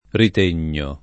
ritegno [ rit % n’n’o ] s. m.